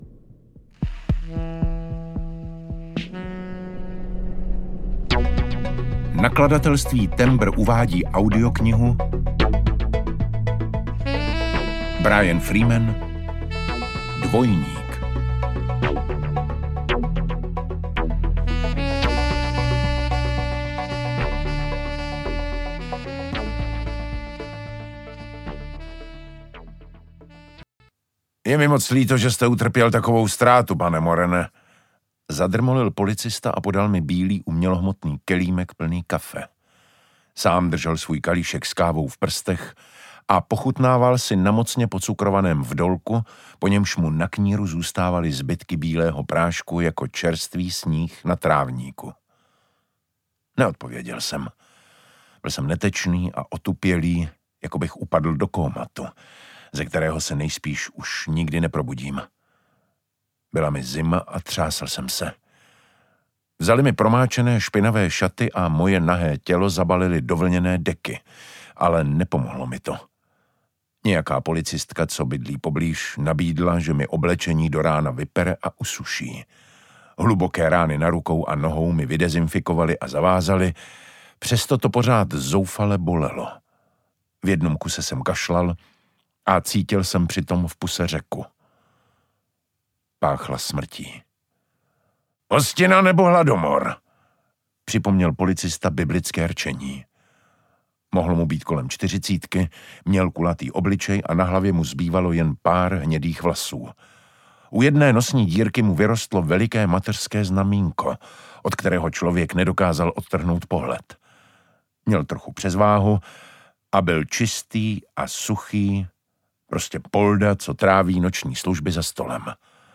Dvojník audiokniha
Ukázka z knihy
• InterpretDavid Matásek